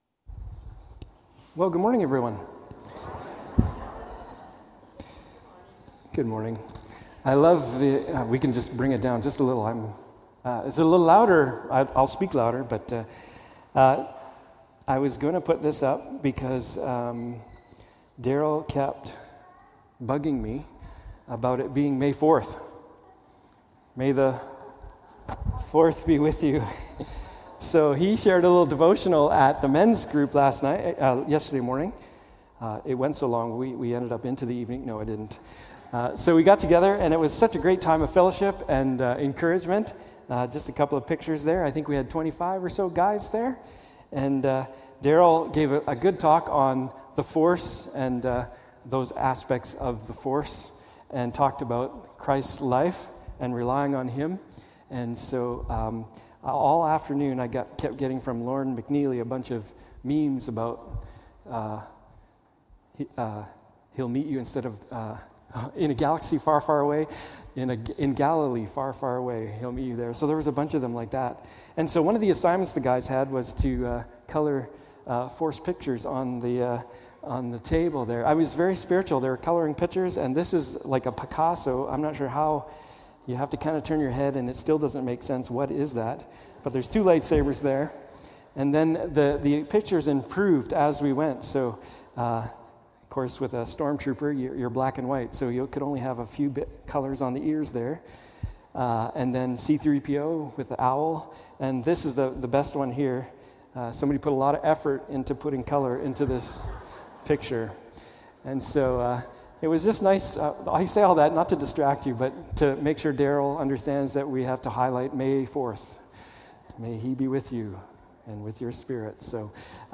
Passage: Mark 14:1-31 Service Type: Morning Service